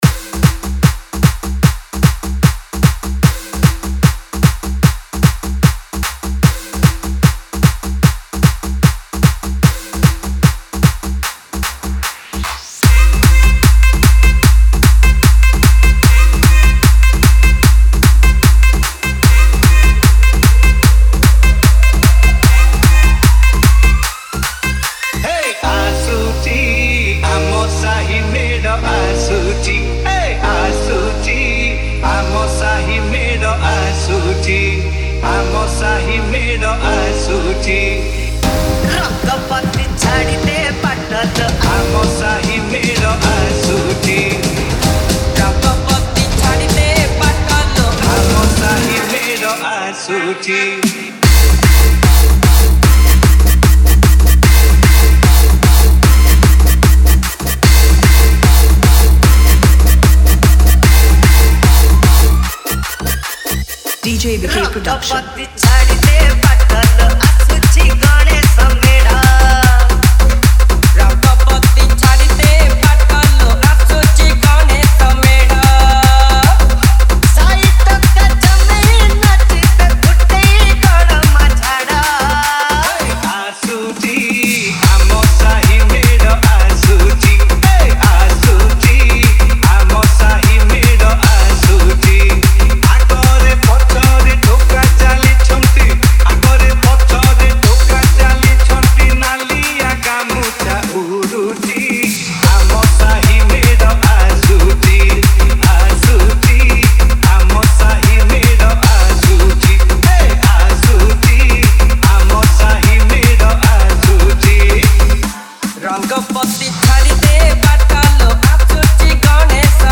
Ganesh Puja Special Dj 2023 Songs Download